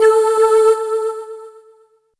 voiTTE64005voicesyn-A.wav